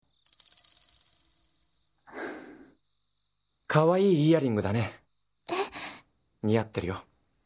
take a listen to the sweet sounds of Miki; as Yougi Kudou, from the anime Weiss Kreuz.